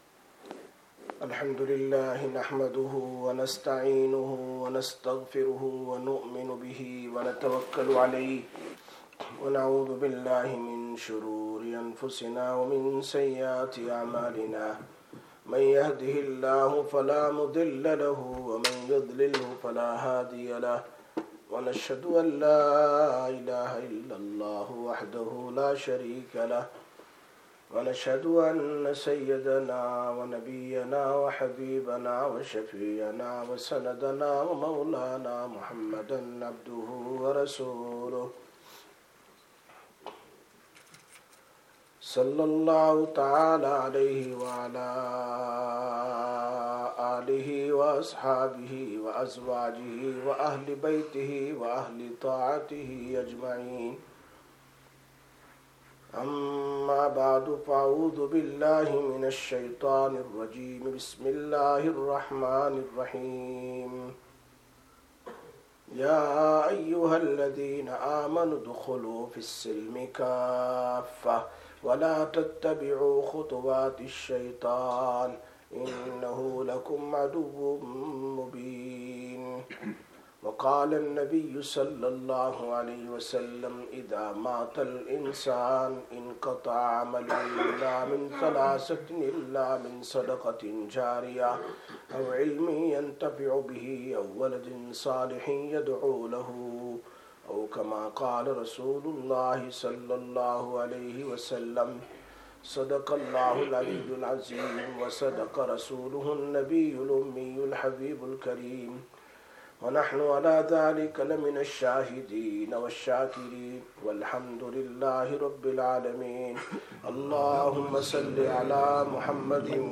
18/07/2025 Jumma Bayan, Masjid Quba